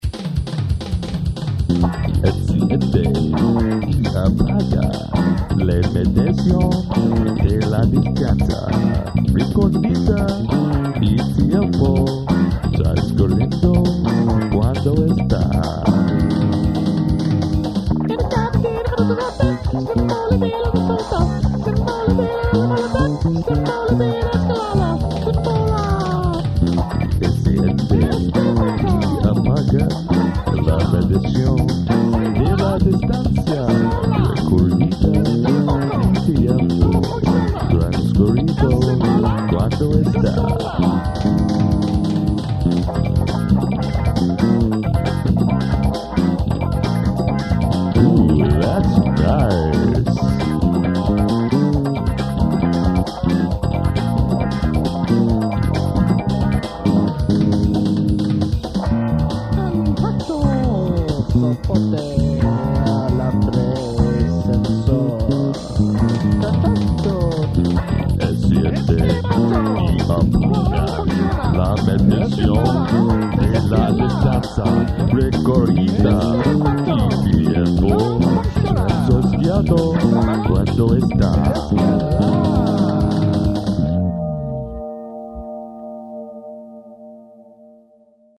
I have chosen a select few here that were recorded in the living room studio in "The Apartment" in the late '90s, around '98 or so.
These songs were written in extreme haste, recorded with more haste, with very little attention to detail, usually each instrument in one take only.
bass, main vox